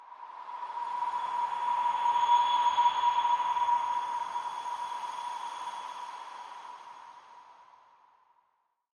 Радио и рингтоны » Звуки » Атмосферные фоны » Звуки звёзд
Откройте для себя удивительные звуки звёзд – космические мелодии, которые перенесут вас в мир фантазий и вселенских тайн. Эта коллекция идеально подходит для расслабления, медитации или просто мечтаний под мерцание далёких светил.
Звуки далеких звезд, которые почти невозможно услышать